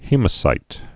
(hēmə-sīt)